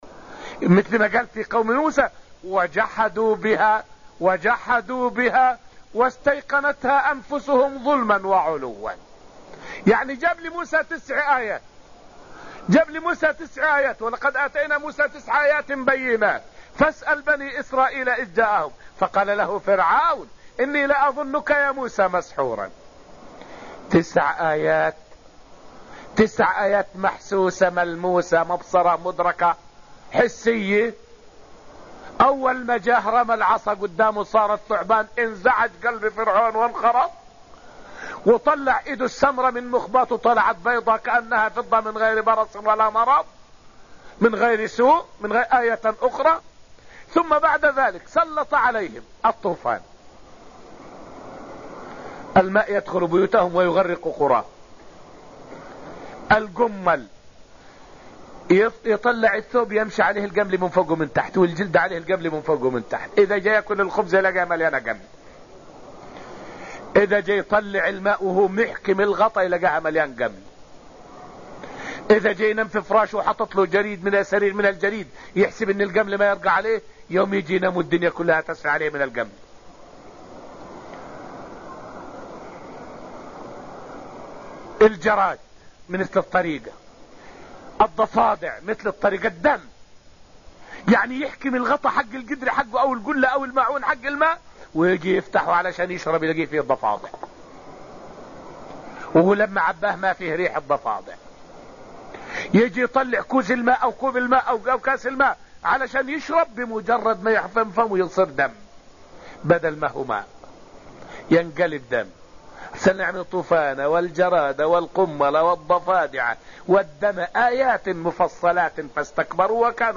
الذاريات تفسير تفسير القرآن دروس المسجد النبوي قصص الأنبياء
فائدة من الدرس الرابع من دروس تفسير سورة الذاريات والتي ألقيت في المسجد النبوي الشريف حول نموذج الجحود السافر في قصة موسى مع فرعون وقومه.